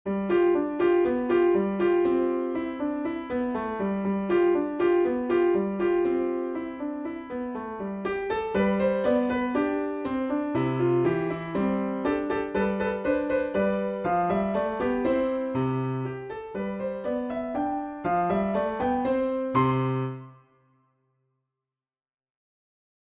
A Beginner Grade 1 Piano Piece